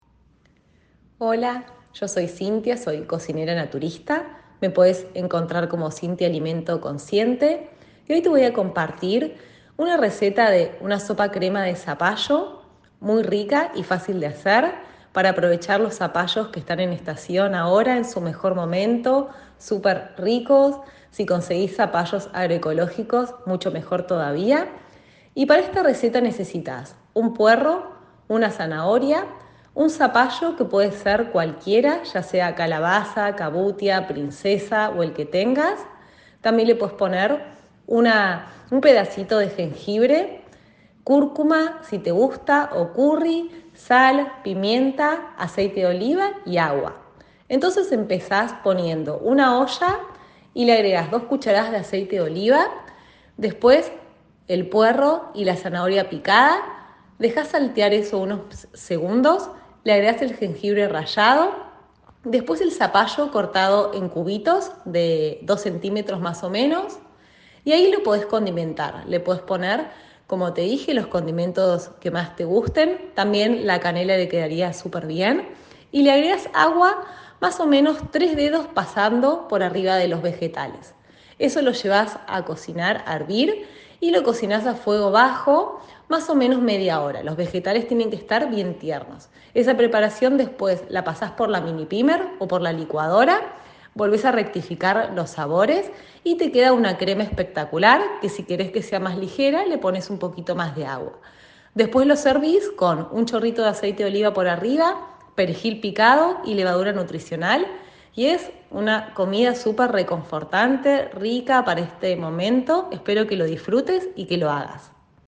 Audio receta de sopa crema de zapallo y la propuesta de una alimentación consciente